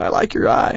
gutterball-3/Gutterball 3/Commentators/Bill/b_likeyoureye.wav at 19901ee7a9e2ec02a974f2d9b9fa785384d4e897